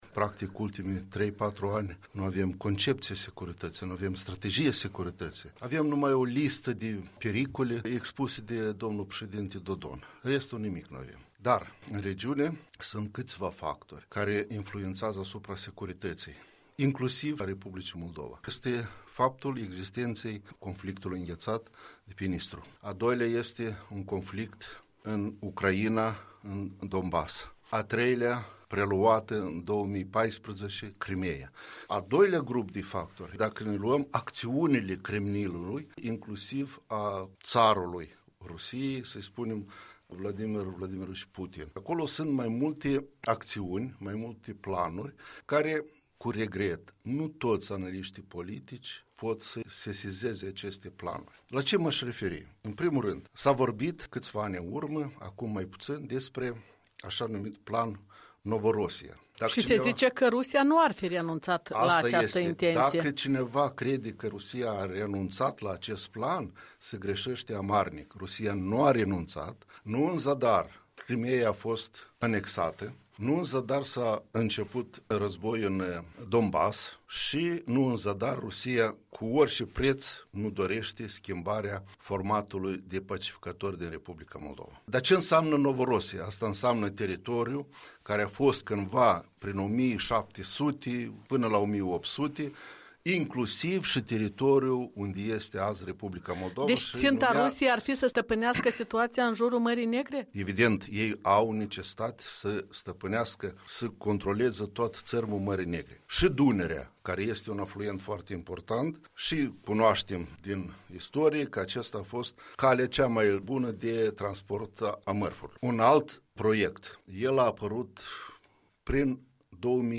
Valentin Dediu în studioul Europei Libere de la Chișinău